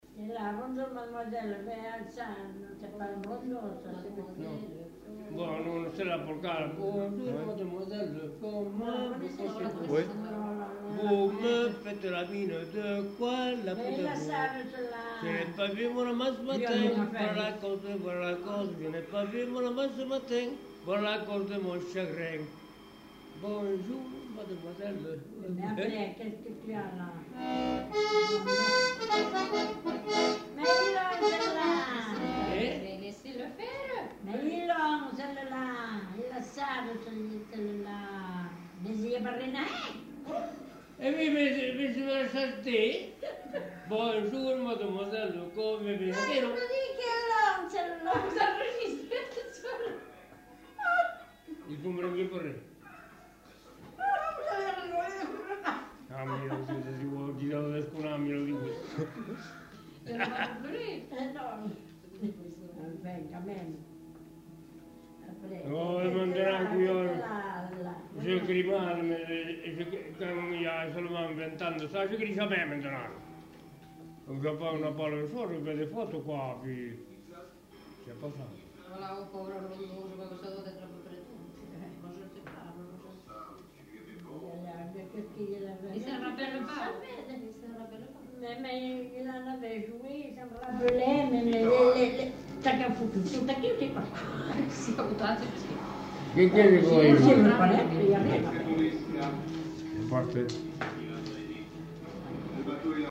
Aire culturelle : Savès
Lieu : Pavie
Genre : chant
Effectif : 1
Type de voix : voix d'homme
Production du son : chanté
Danse : polka piquée